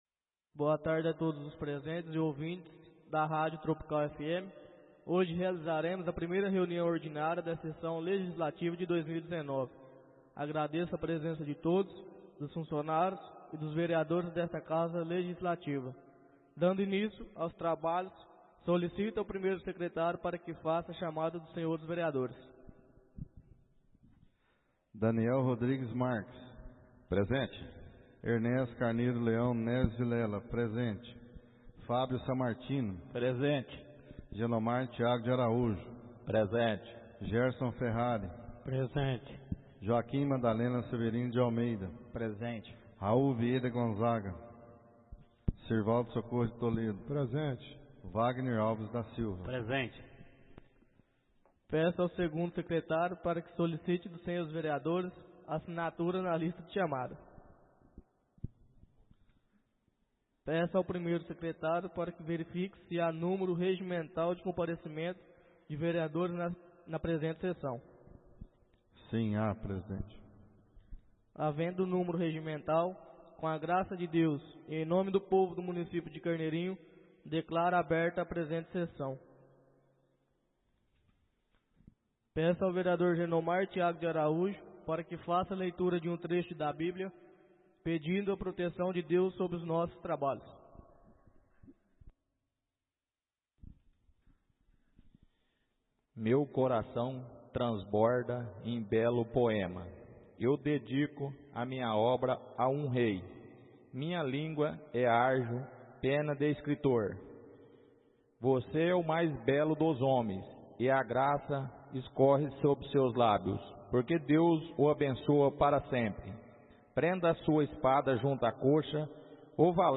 Áudio da 01.ª reunião ordinária de 2019, realizada no dia 04 de Fevereiro de 2019, na sala de sessões da Câmara Municipal de Carneirinho, Estado de Minas Gerais.